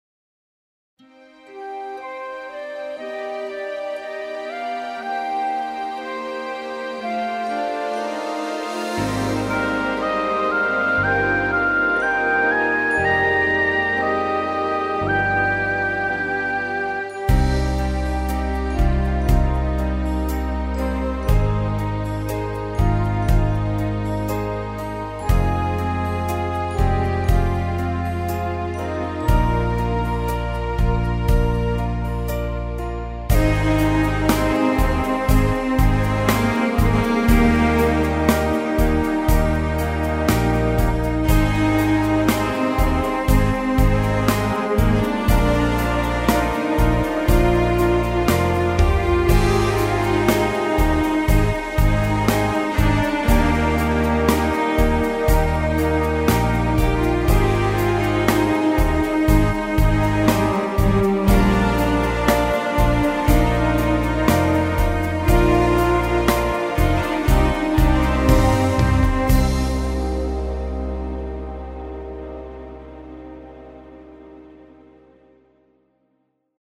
Orchestermusik, wie bei einem Livemusical.